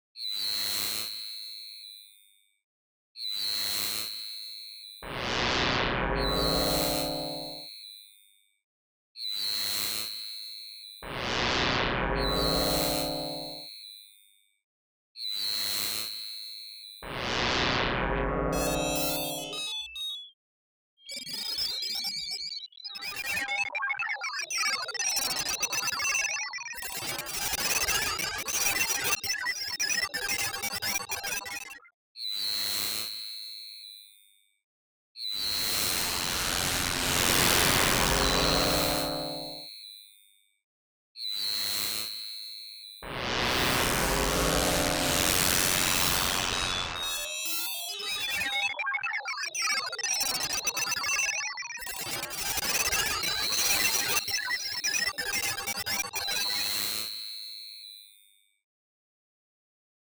Modulate -- A little FM synthesis for this Thursday evening.